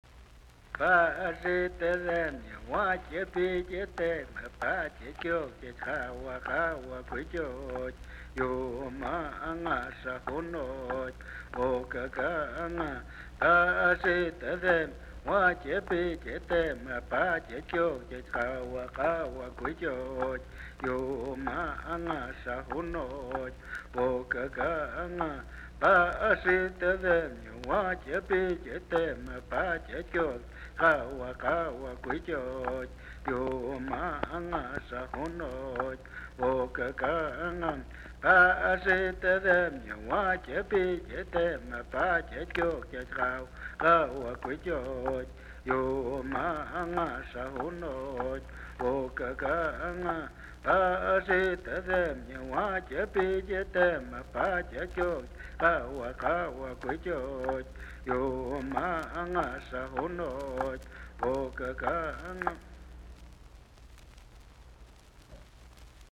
Recorded in Indian communities by Willard Rhodes, with the cooperation of the United States Office of Indian Affairs.